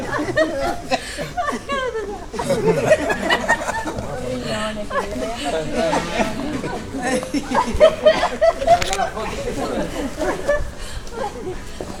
Tavern / Loops / Chatter
chatter-3.ogg